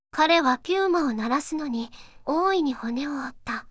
voicevox-voice-corpus
voicevox-voice-corpus / ita-corpus /四国めたん_セクシー /EMOTION100_007.wav